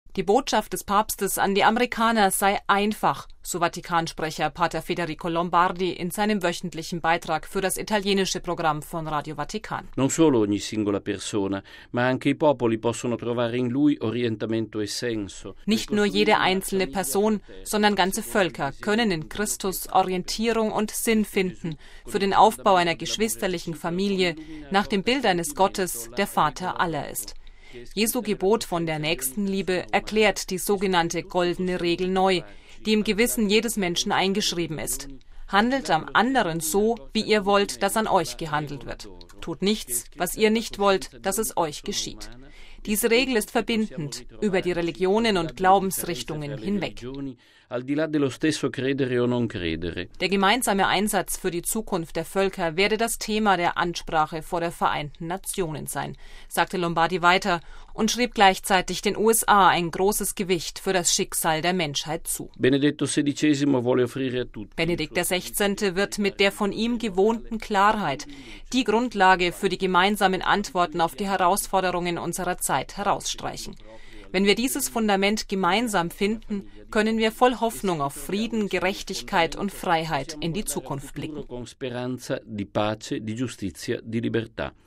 MP3 Die Botschaft des Papstes an die Amerikaner sei einfach, so Vatikansprecher Pater Federico Lombardi SJ in seinem wöchentlichen Beitrag für das italienische Programm von Radio Vatikan: